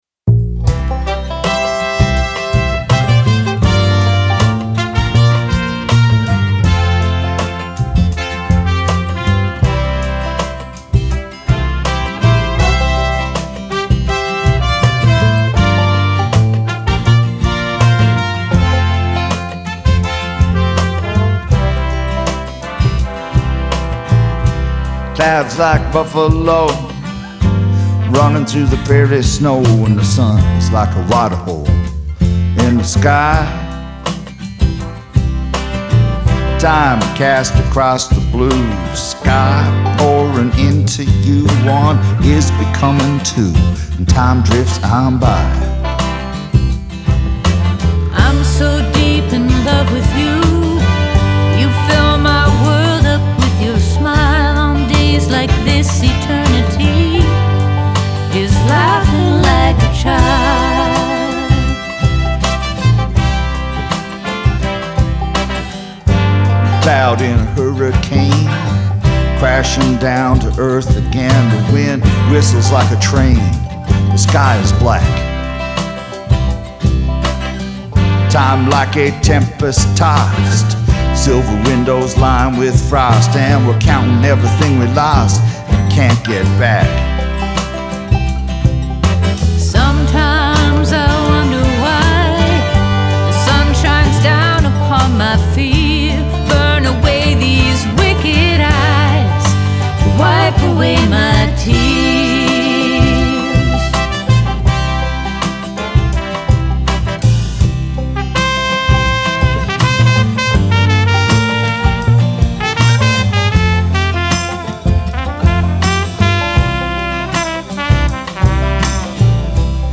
The song features a stellar horn arrangement and performance